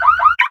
CarLock.ogg